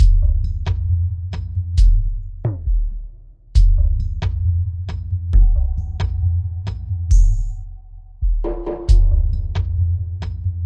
Ambient Groove " Ambient Groove 005
描述：为环境音乐和世界节奏制作。完美的基础节拍。
Tag: 环境